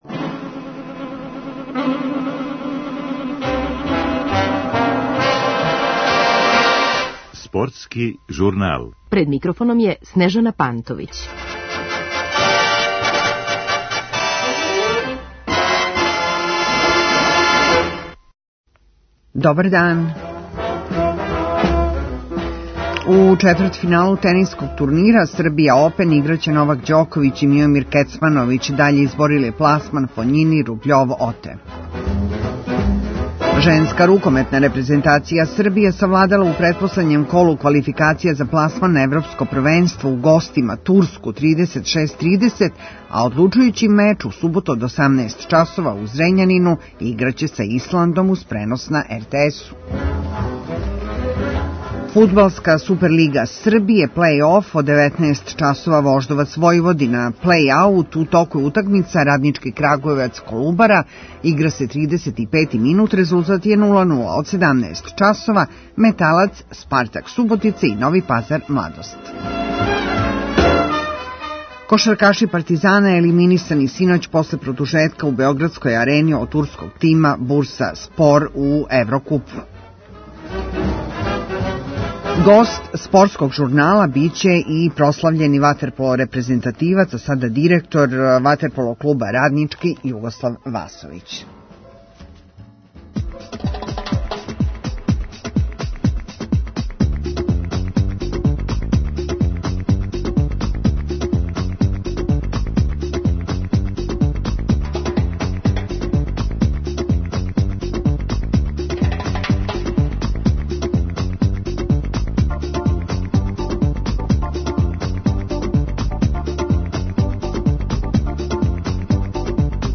У центру пажње журнала биће тениске актуелност са турнира Сербиа Опен на теренима на Дорћолу у Београду, где су репортери Радио Београда.